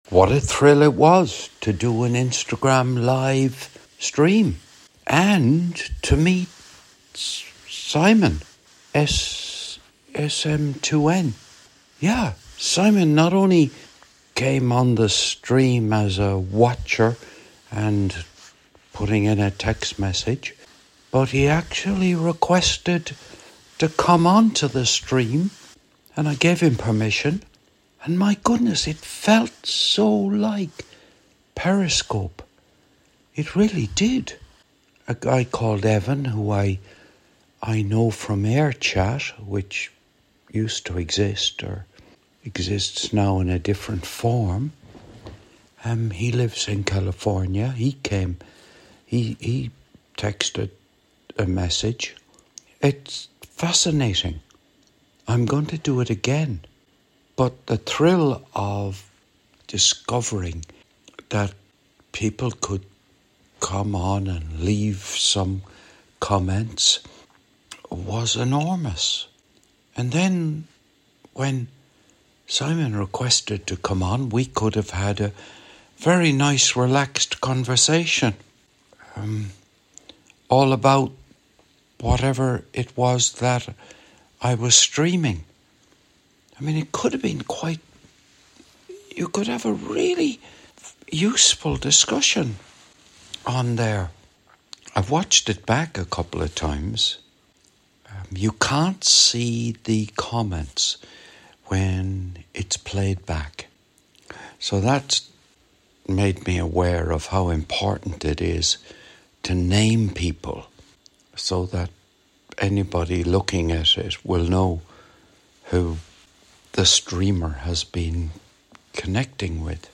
this was recorded on the morning of Thursday 13th of February 2025 - in my kitchen